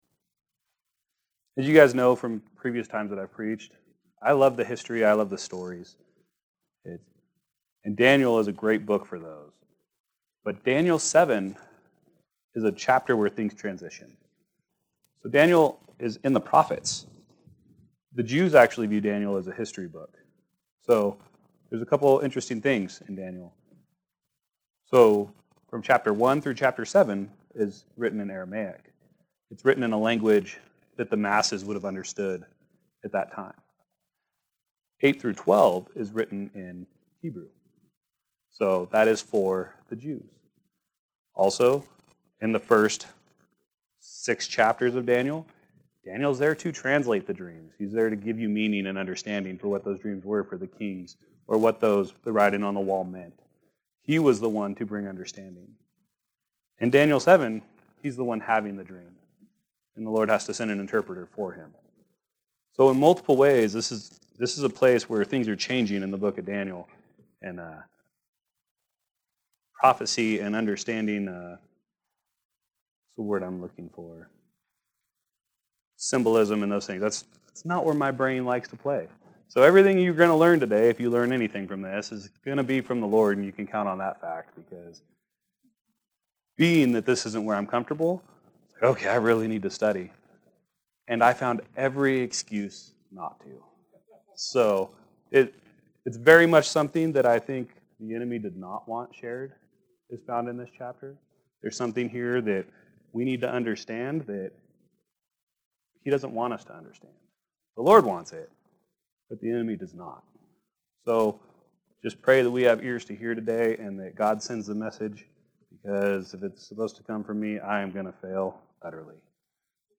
Our Sermons